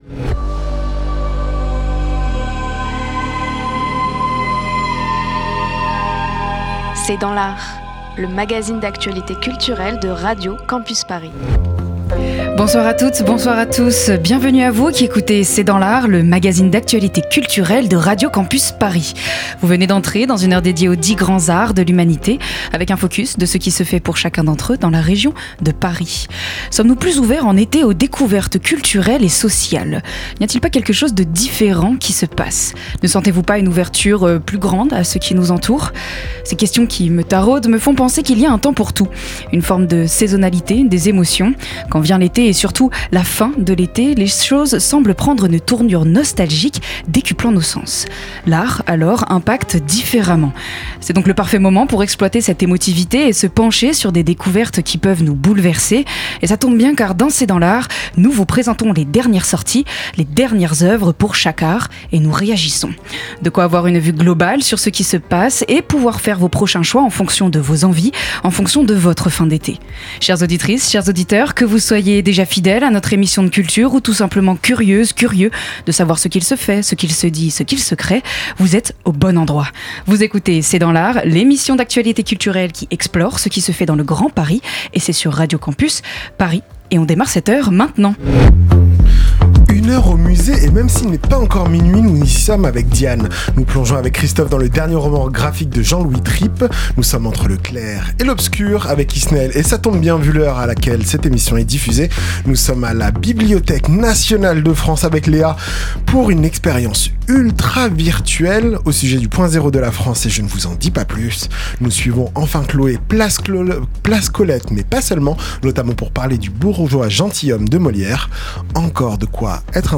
C’est dans l’art, c’est l’émission d'actualité culturelle de Radio Campus Paris.